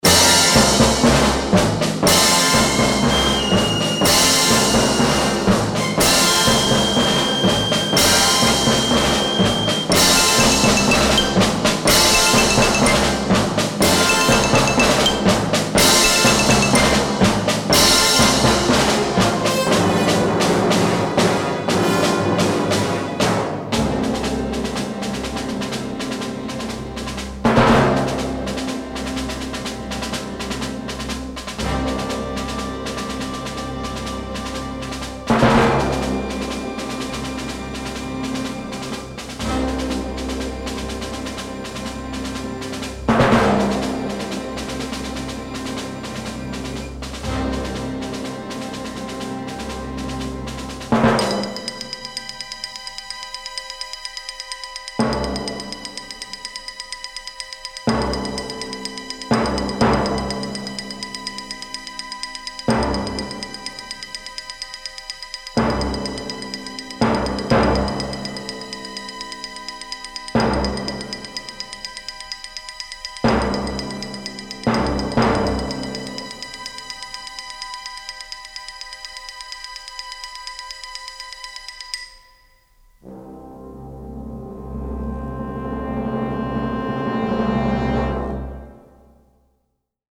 orchestral score